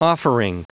Prononciation du mot offering en anglais (fichier audio)
Prononciation du mot : offering